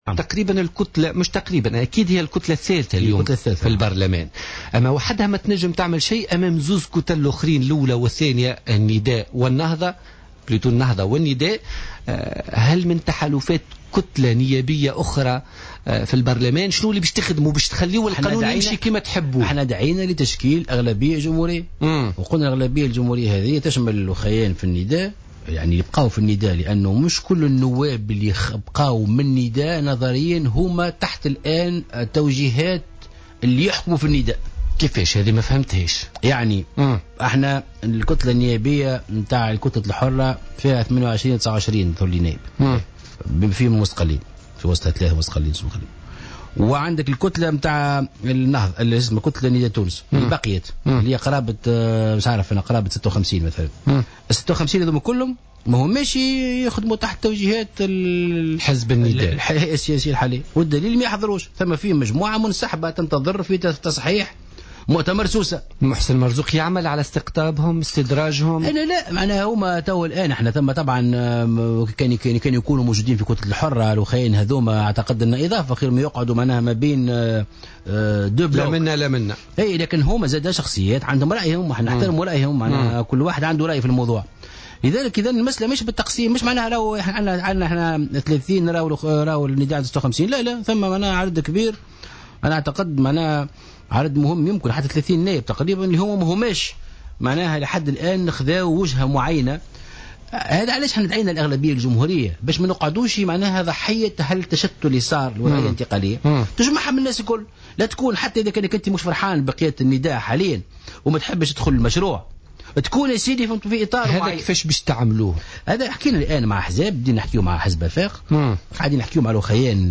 وأضاف مرزوق، ضيف برنامج "بوليتيكا" اليوم الاثنين أن هذه "الجبهة الجمهورية" تشمل مختلف الأحزاب التي صوتت لرئيس الجمهورية الباجي قائد السبسي في الانتخابات الرئاسية الماضية.